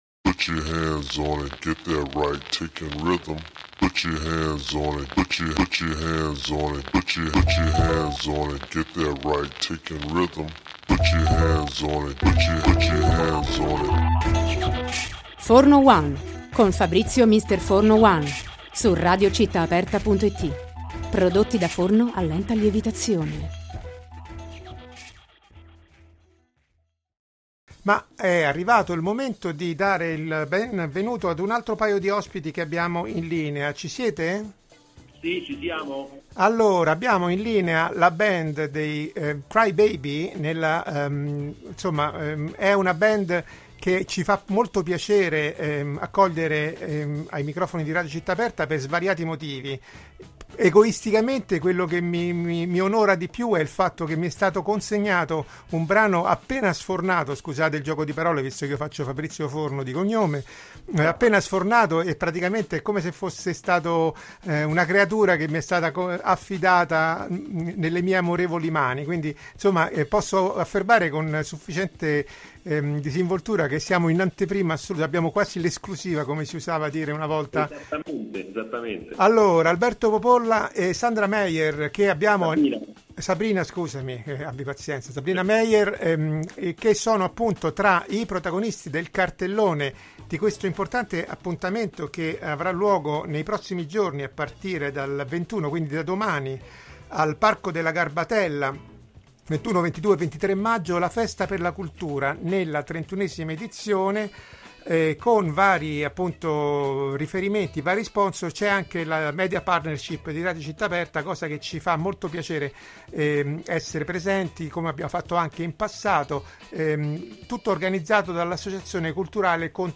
INTERVISTA ALLE CLEOPATRAS - 20.06.2024